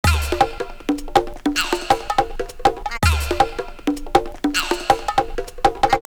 2_DrumLoops_3.wav